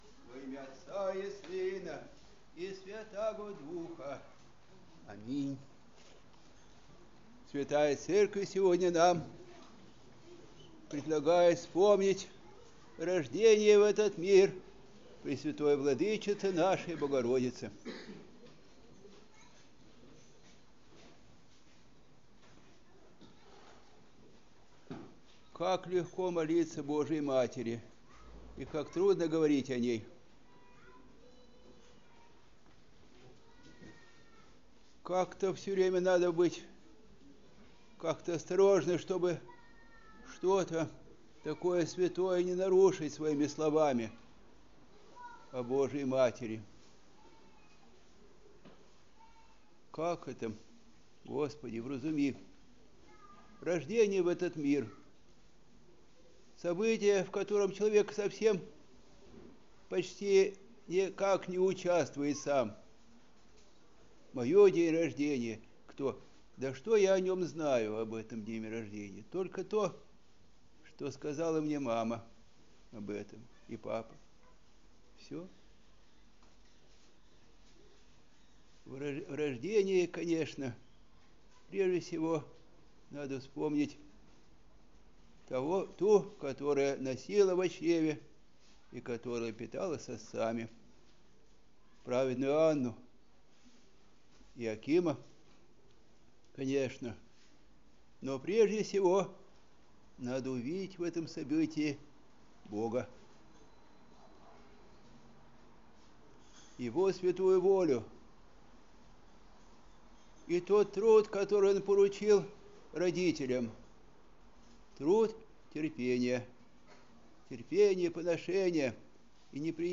Проповедь прот.